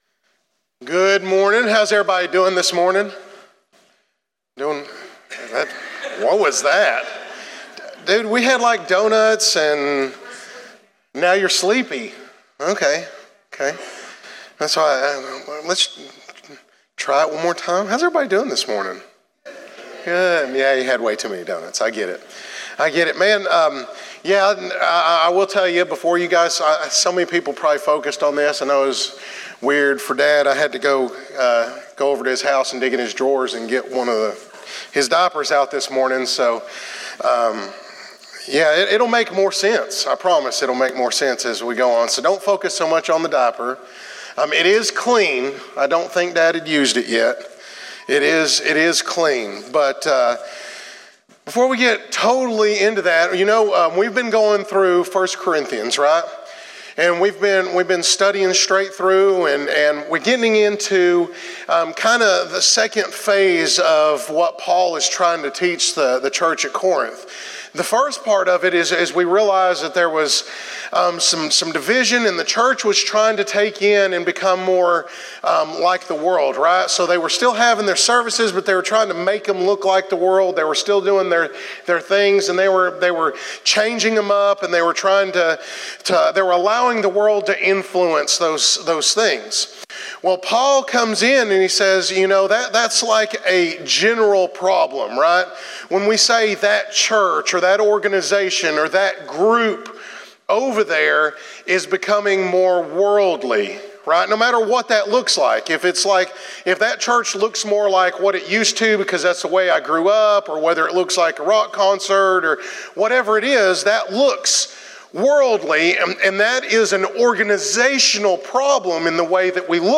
sermon.cfm